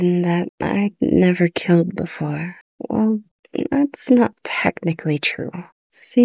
以下使用 NVIDIA GeForce RTX 4070 大约半小时微调后的 IndexTTS 所生成的中英文语音音频样例：
参考音频合成的语音试听